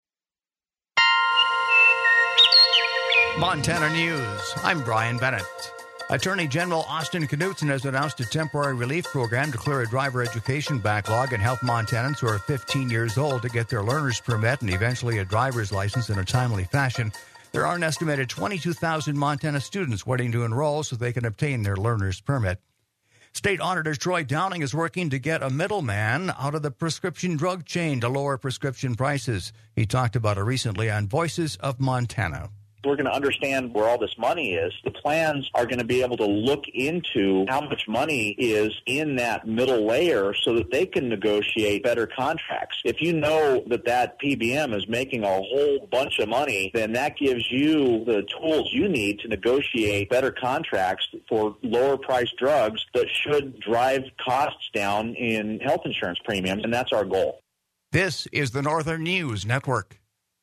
State Auditor Troy Downing is working to get a middleman out of the prescription drug chain to lower prescription prices. He talked about it recently on Voices of Montana.